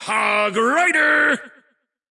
HogRider_intense_2.wav